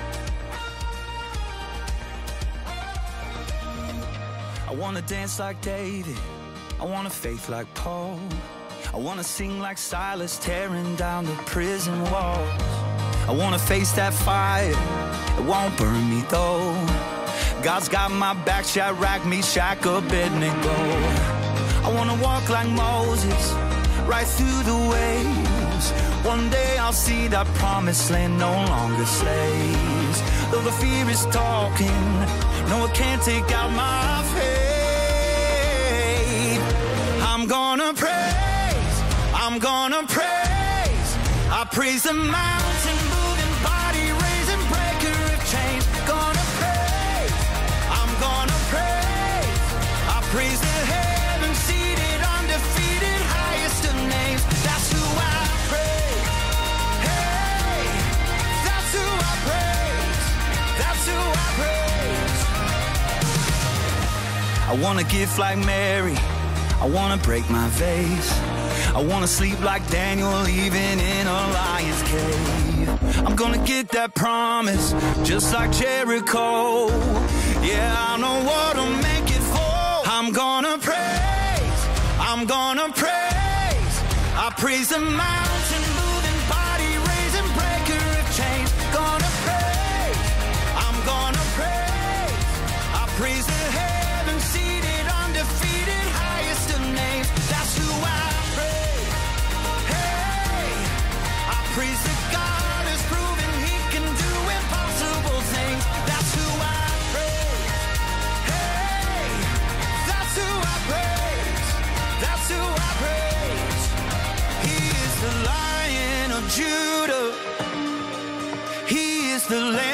A special Sunday service as we celebrate God’s faithfulness over the last 75 years and look to the future for how He’s continuing to move and shape us for His glory!